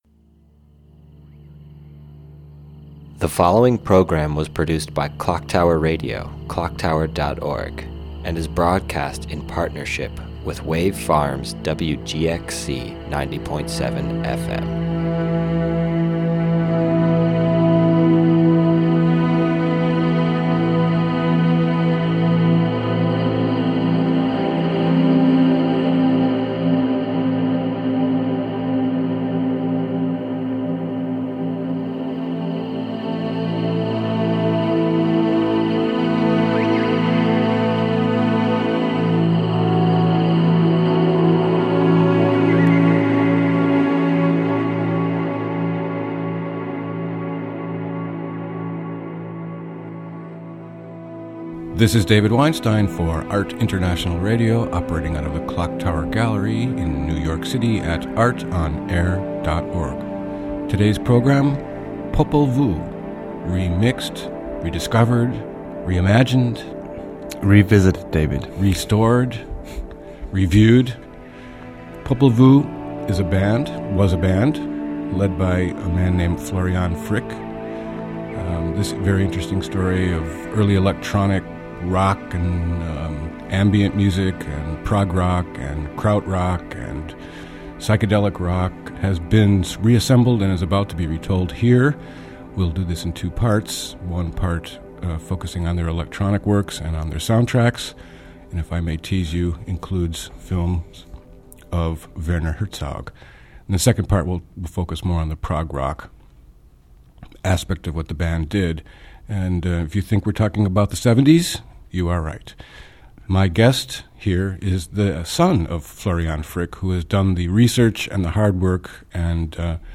A listening session and conversation